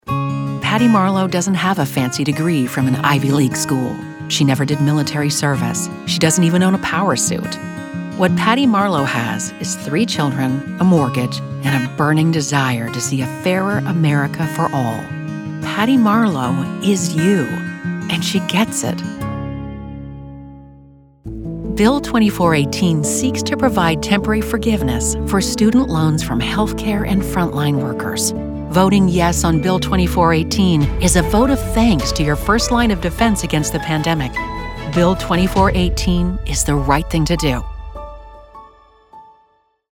Annonces politiques
Avec une cabine StudioBricks et un micro Sennheiser, je produis efficacement un son de qualité studio.
Sennheiser 416 ; cabine StudioBricks ; Adobe Audition ; mixeur Yamaha AG03 ; Source-Connect.